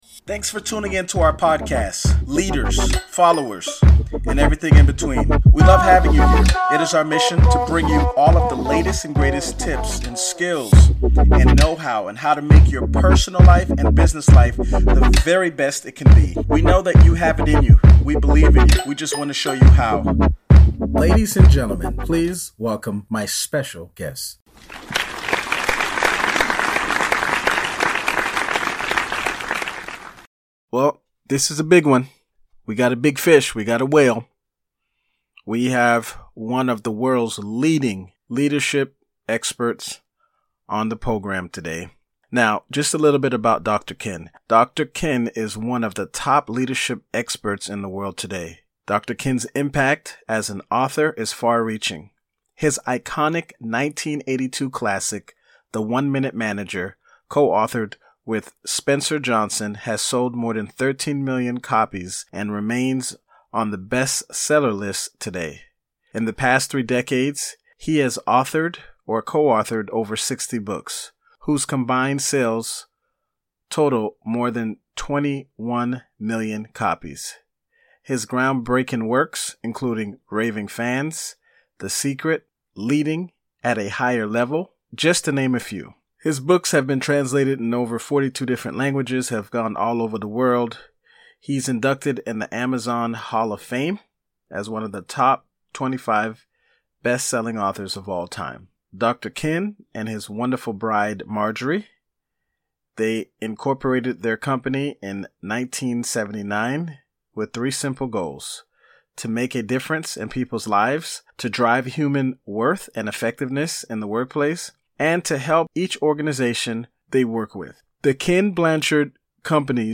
A Conversation on Racial Unity